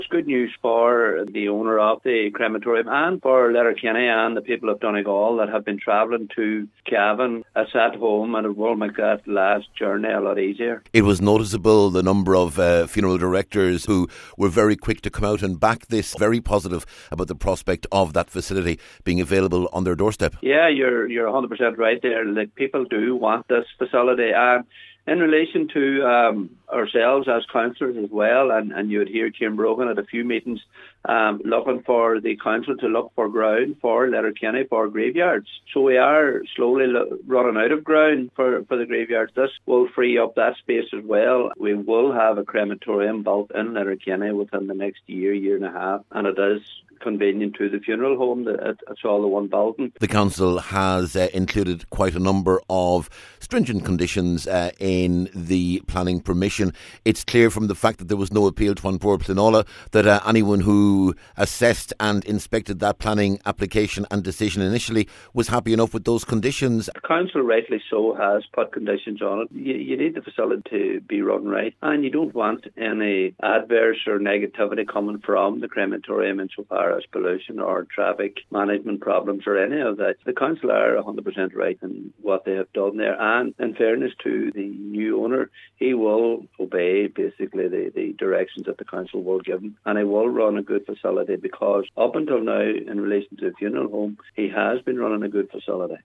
Cllr Paul Canning was involved in the planning process – He says this is the right decision…………